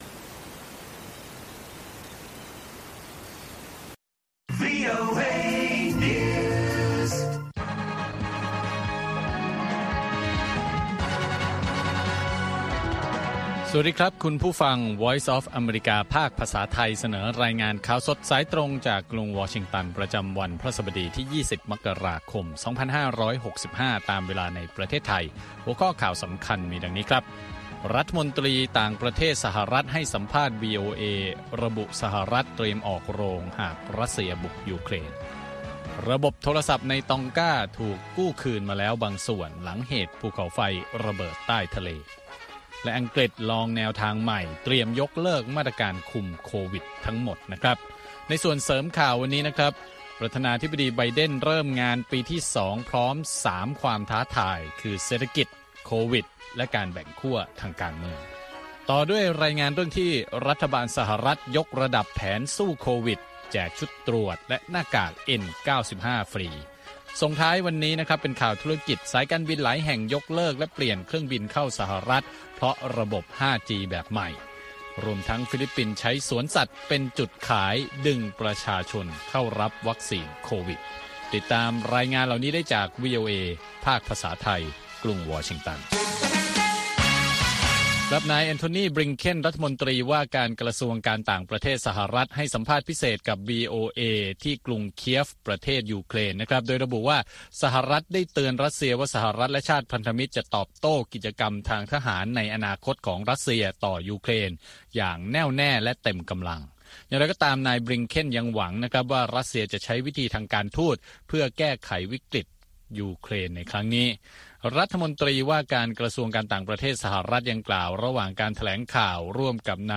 ข่าวสดสายตรงจากวีโอเอ ภาคภาษาไทย 6:30 – 7:00 น. ประจำวันพฤหัสบดีที่ 20 มกราคม 2565 ตามเวลาในประเทศไทย